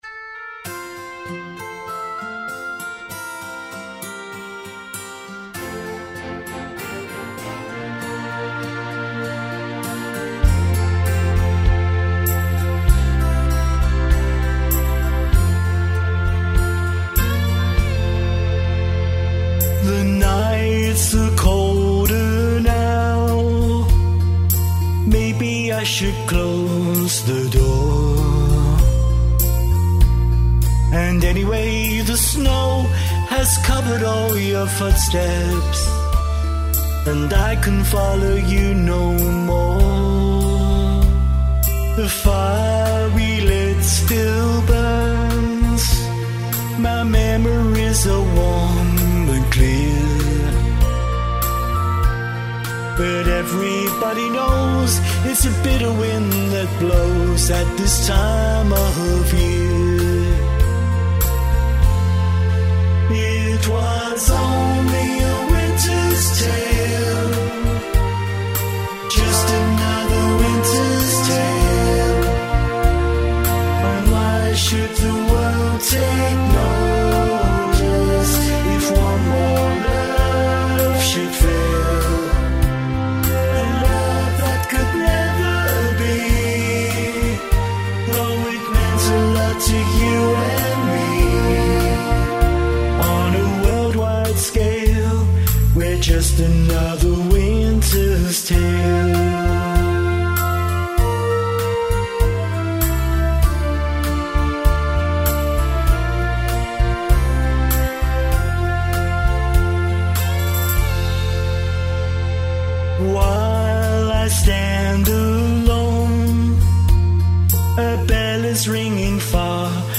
The track is a faithful cover
polished modern production
distinctive vocal style
FREE MP3 Radio Edit Download
UK pop singer
smooth vocals